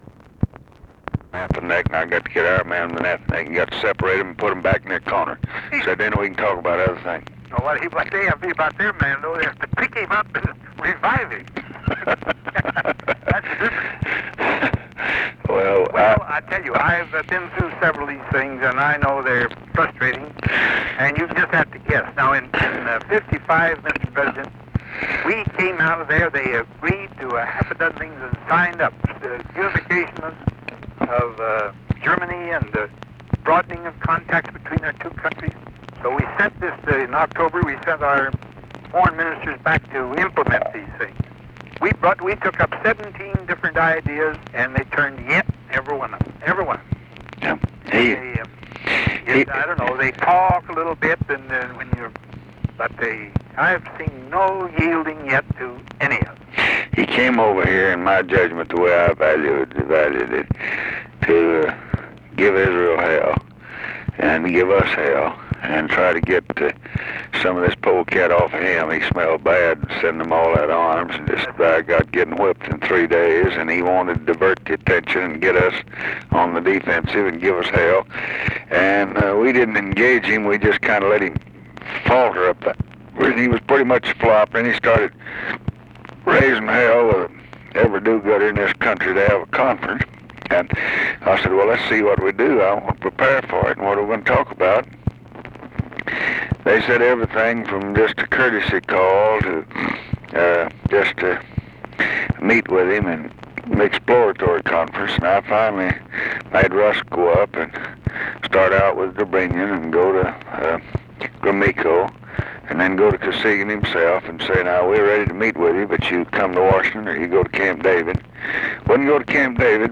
Conversation with DWIGHT EISENHOWER, June 26, 1967
Secret White House Tapes